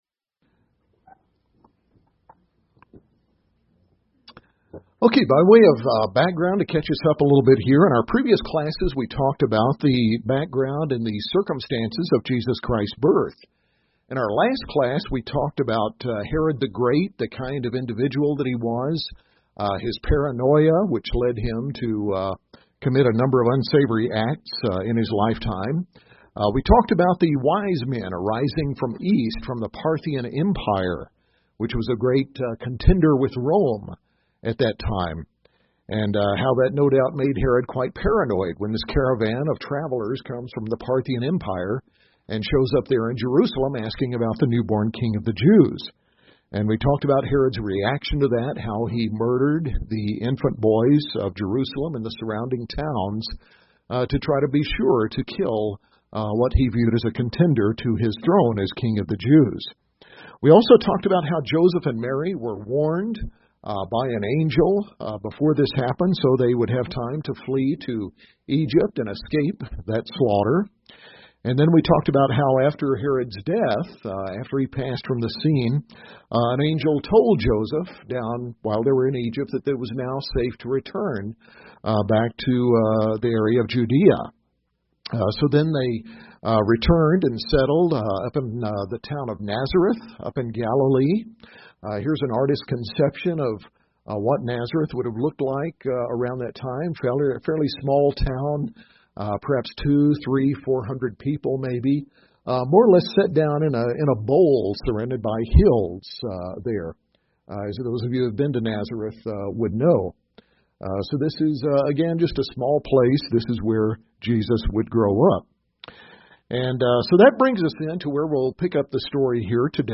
In this class on Luke 2:40-52, we cover Jesus' later childhood, including some of the myths of later spurious writings; the circumstances of Jesus' discussions with teachers at the temple at age 12; the educational system of Galilee and what was expected of Jewish boys; the Jewish teaching method found in the Gospels of asking questions; and how Jesus likely learned of His unique identity as the Son of God.